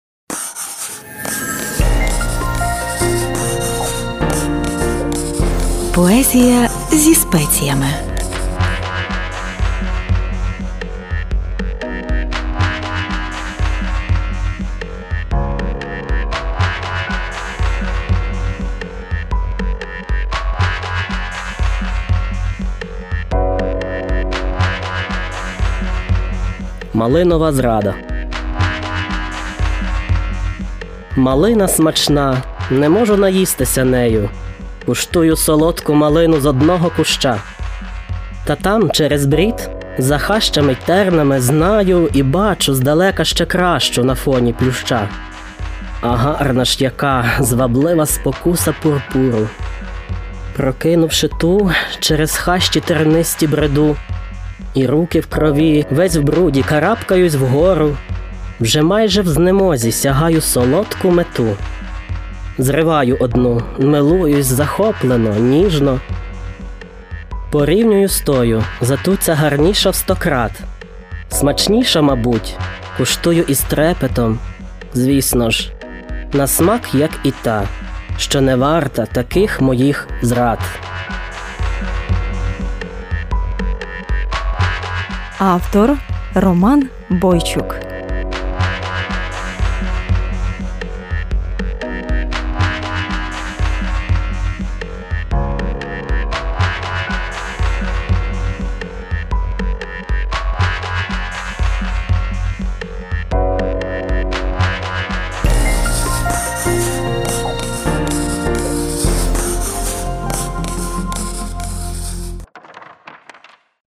МАЛИНОВА ЗРАДА (107 FM - ТРК "ВЕЖА"/ програма "Поезія зі спеціями")
Рубрика: Поезія, Лірика